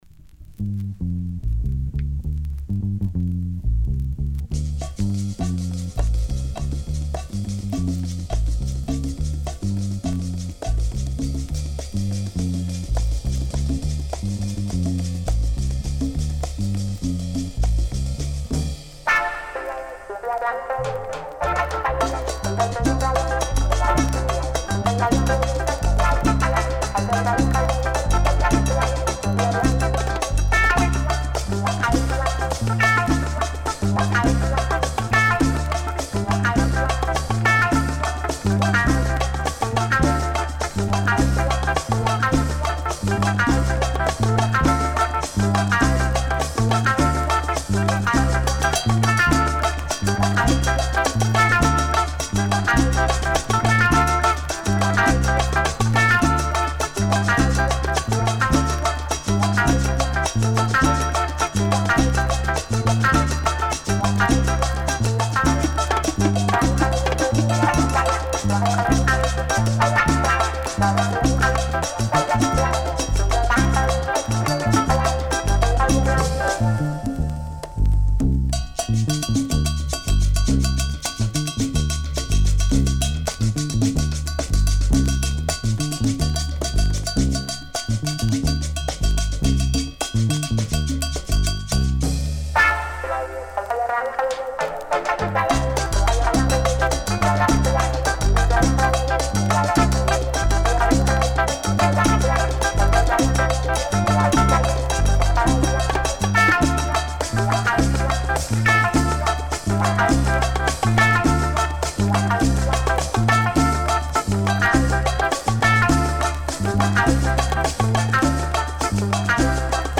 Super deep chicha with a touch of psychedelic rhythms.